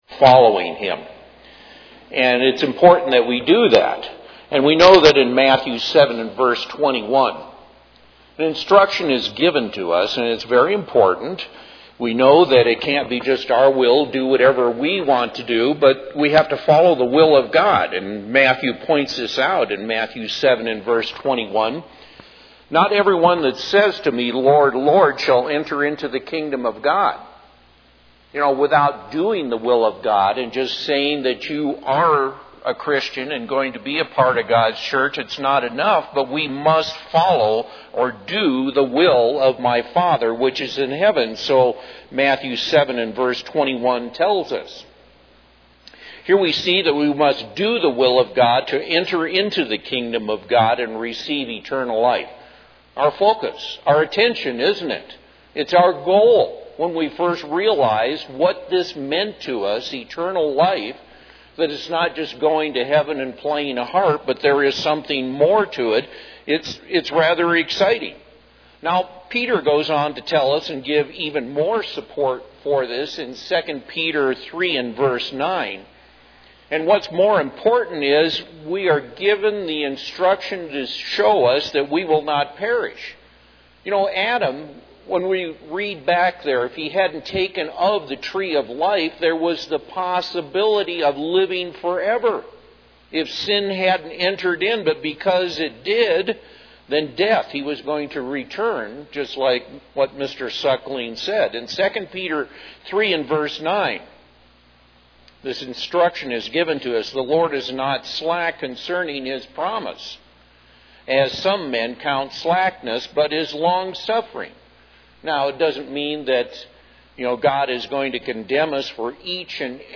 Given in Colorado Springs, CO
UCG Sermon Studying the bible?